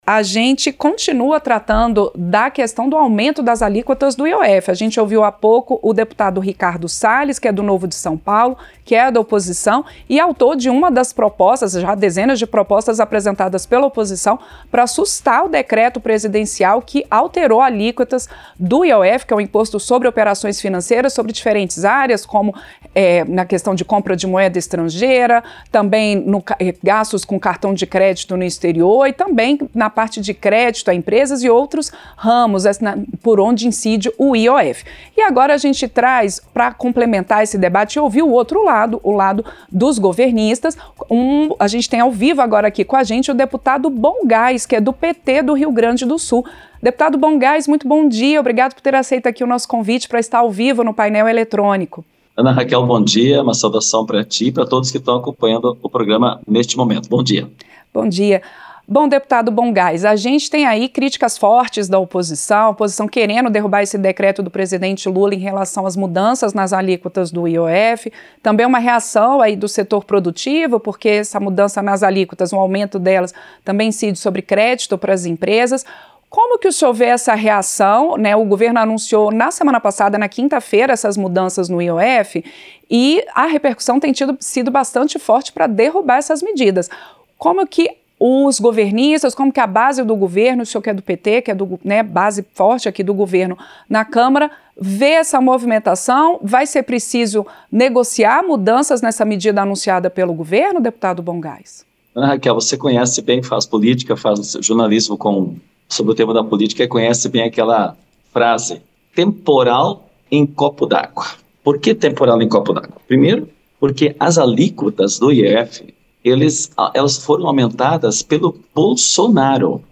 Entrevista - Dep. Bohn Gass (PT-RS)